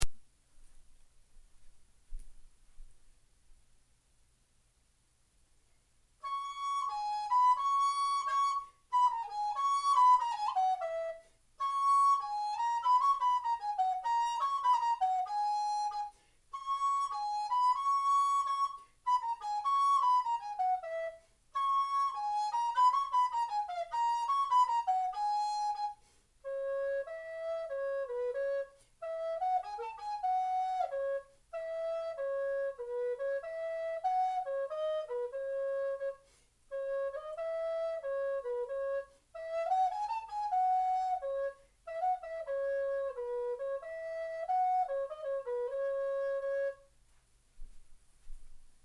jongleurs-solo-alto-415.mp3